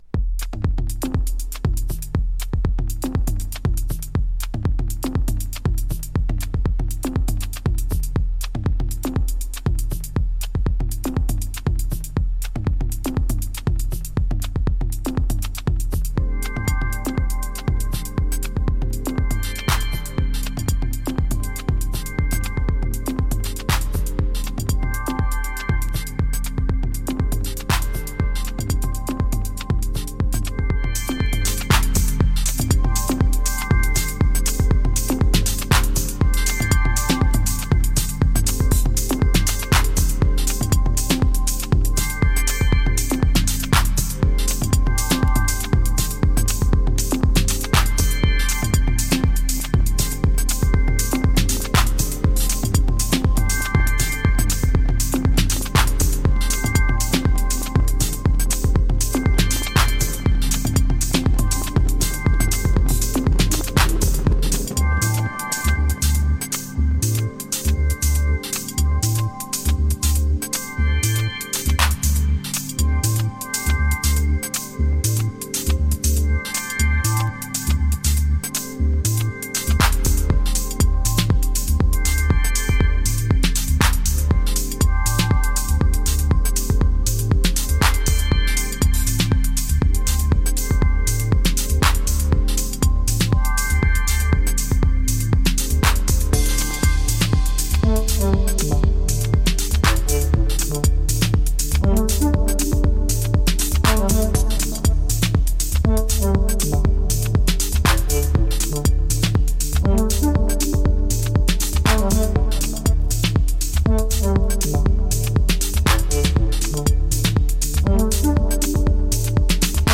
New Release Deep House House